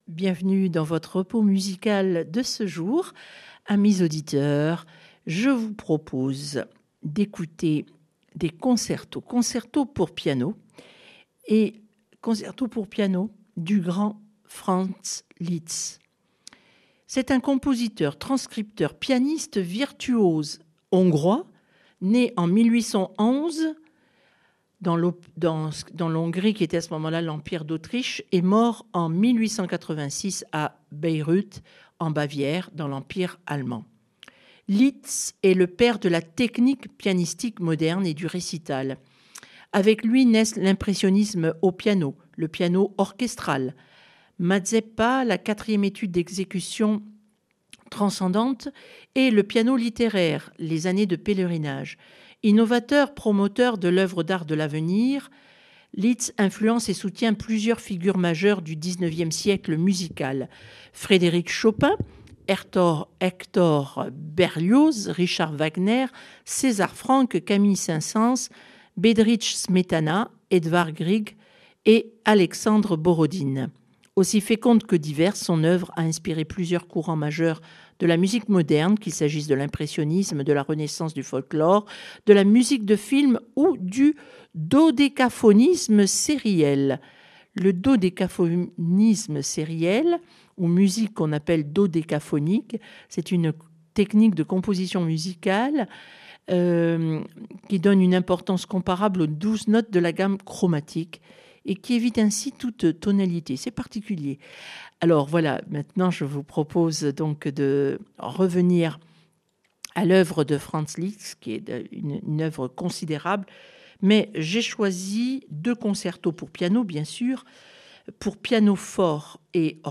FRANZ LITZ concertos pianos